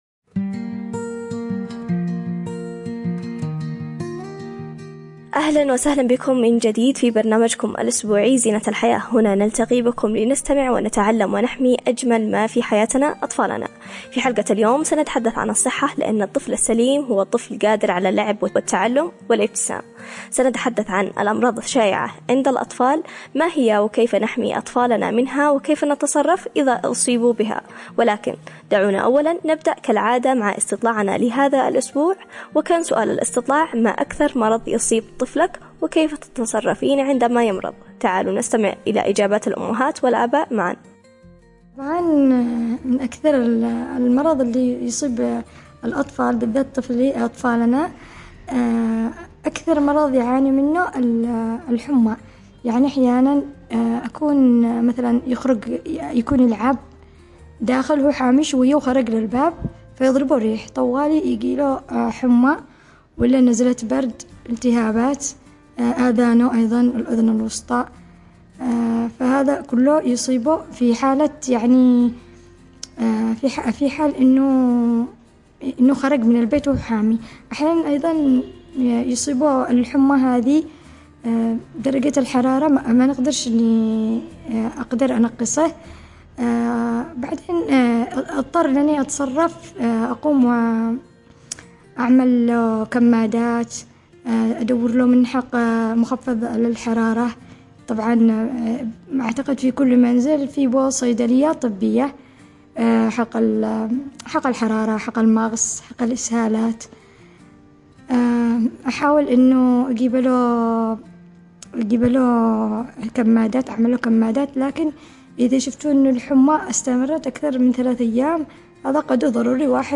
حوار تثقيفي
📻 على أثير إذاعة رمز